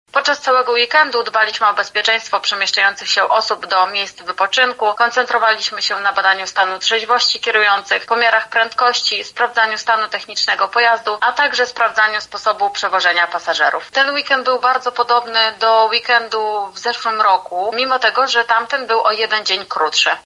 • mówi młodsza aspirantka